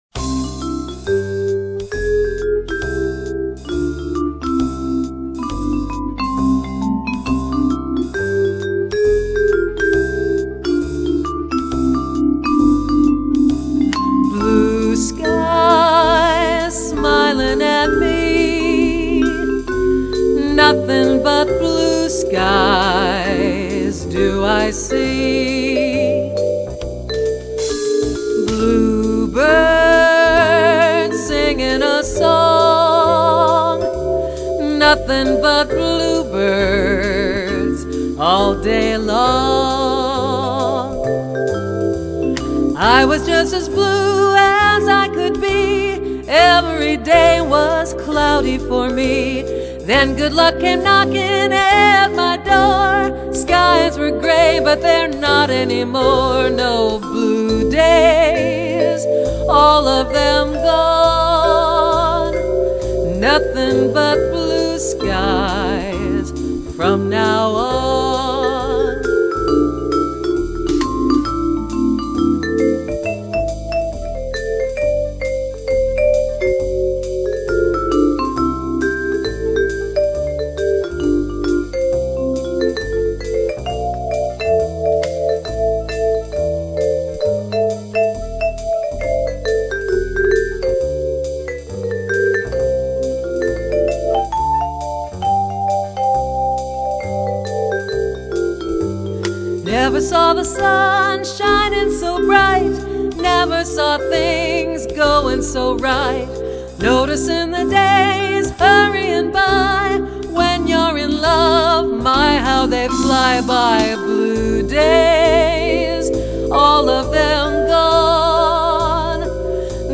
Solo performances use pre-programmed accompaniment tracks.
This makes the music sound full, classy & fun.